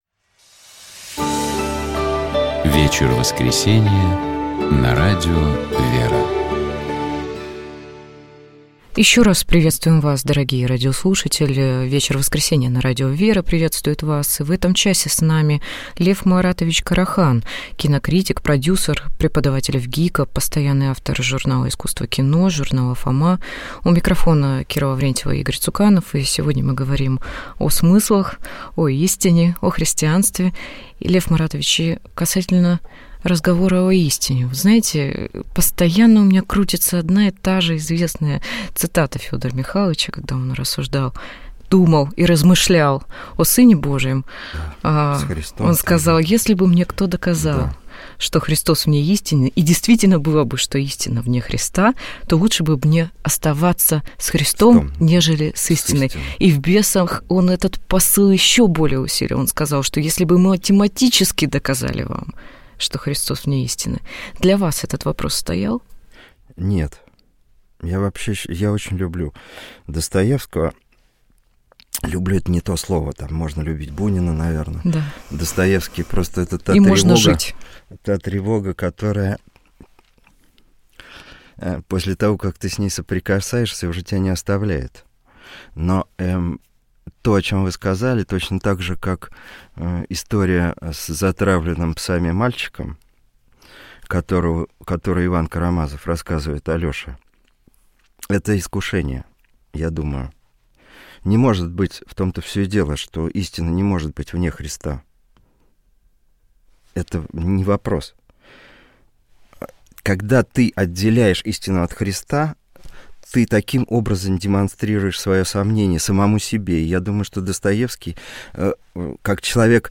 У нас в гостях был кинокритик, продюсер